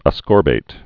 (ə-skôrbāt, -bĭt)